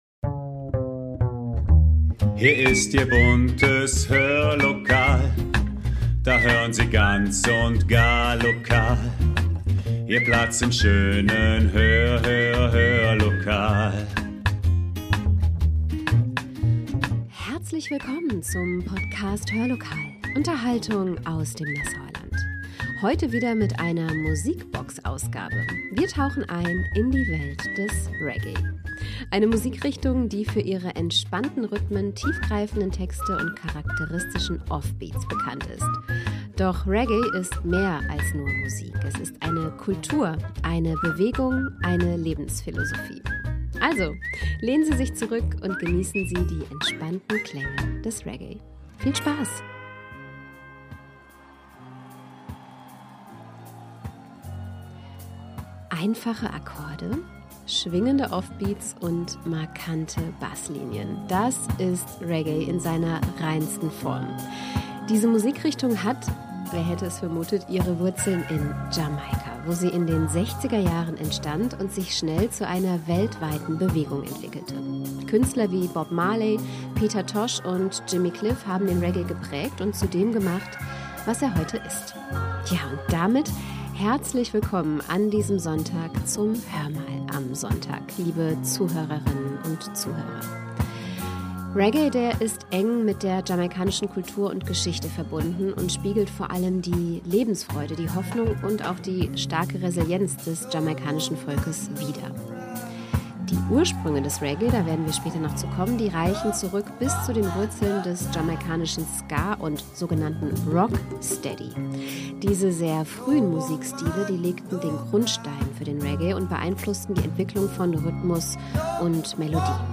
Sie nimmt uns mit zu den warmen und besonderen Klängen des Reggae, der durch Musiker wie Bob Marley seit vielen Jahrzehnten fest zum mittelamerikanischen Kulturgut gehört.